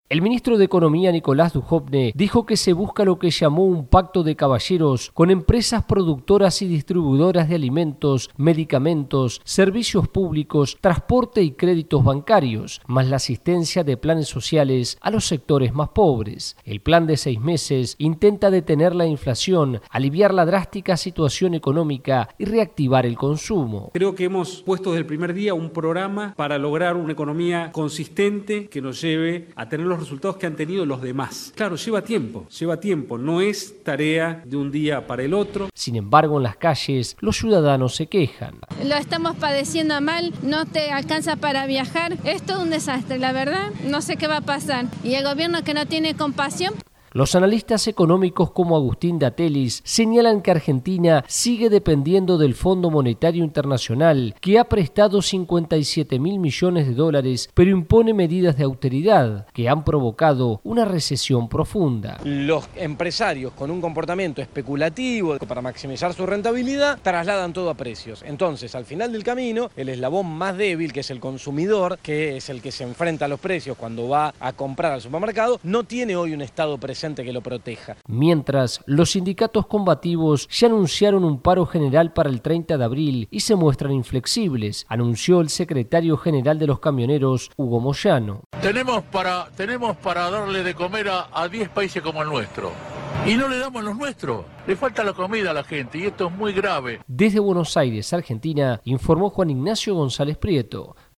VOA: Informe desde Argentina